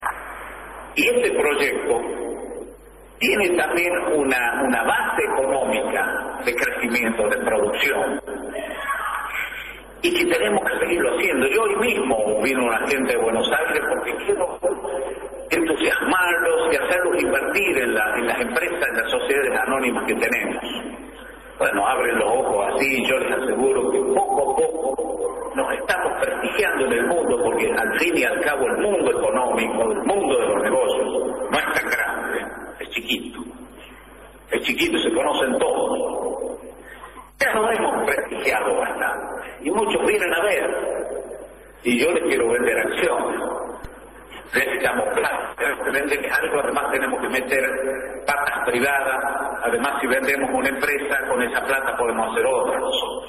El gobernador Luis Beder Herrera anunció en la noche del jueves la privatización de las empresas estatales, conocidas como SAPEM (Sociedades Anónimas con Participación Estatal Mayoritaria), en el marco de un encuentro con dirigentes justicialistas, que se desarrolló en el Paseo Cultural “Castro Barros”.
El encuentro fue encabezado por el gobernador, quien estuvo acompañado por el vicegobernador Sergio Casas, quienes fueron además los únicos oradores.